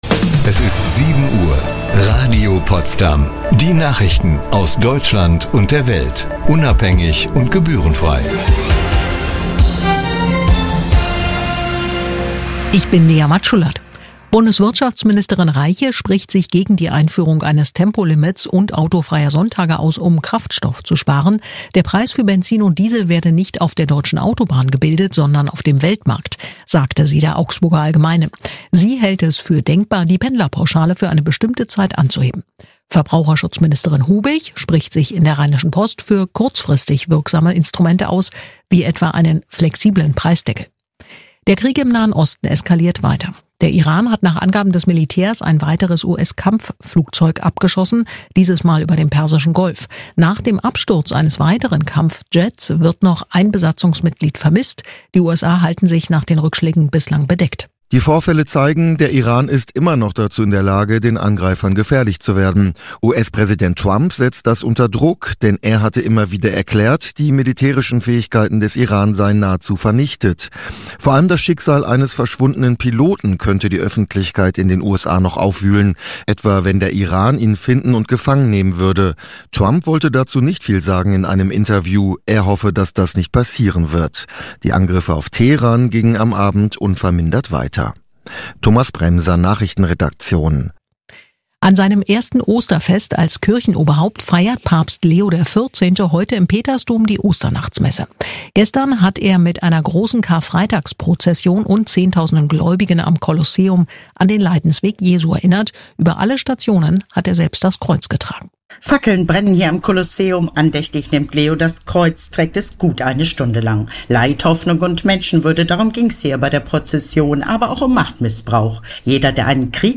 24/7 Aufnahme des Radio-Streams